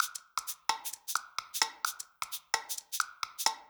Index of /musicradar/uk-garage-samples/130bpm Lines n Loops/Beats
GA_PercE130-02.wav